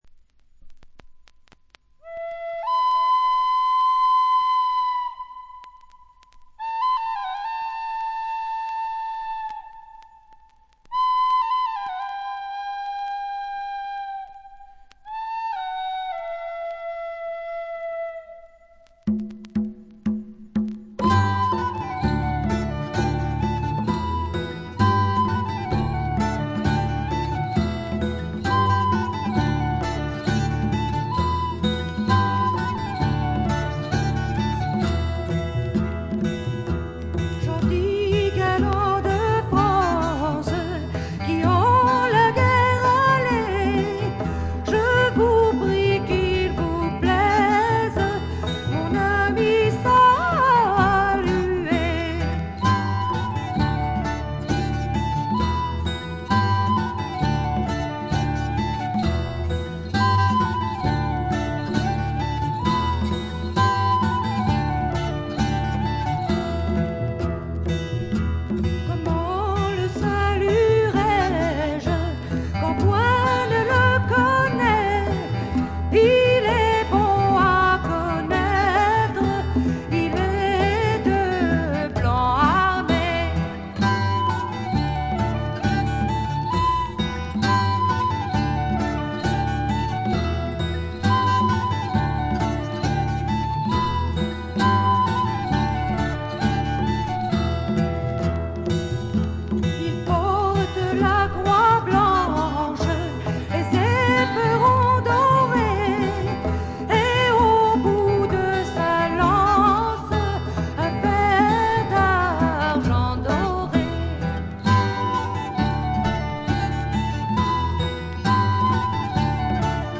静音部の微細なチリプチのみでほとんどノイズ感無し。
フランスの女性フォーク・シンガー。
試聴曲は現品からの取り込み音源です。
Vocals
Guitar, Autoharp, Harmonica, Flageolet
Double Bass
Percussion, Violin
Recorded At - Studio D'Auteuil